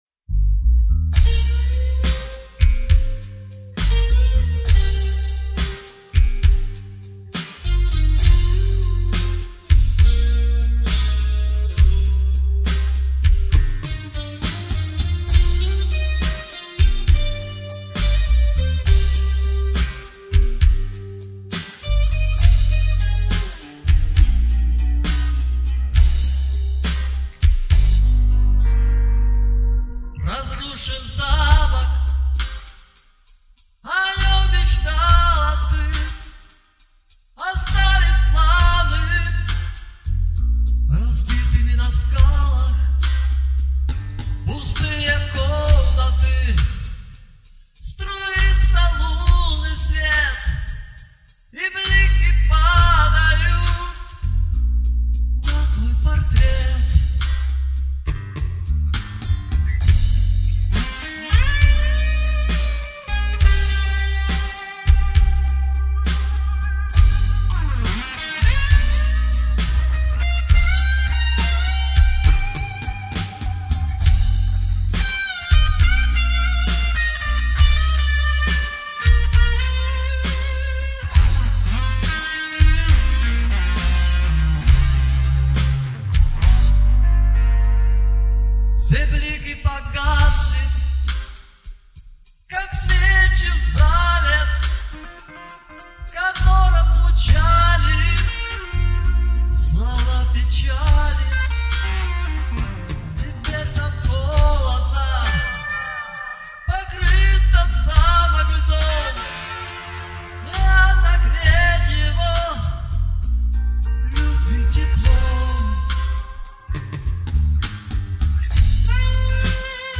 А сведено неплохо!
Спасибо за такую оценку ребята!Это тем более приятно потому что исходным был mp3 с б.т.-160 с приличным шумом и заметным оффсетом.(И ЧЕТКИМ УКАЗАНИЕМ - ЗРОБЫ ШО НИБУДЬ !)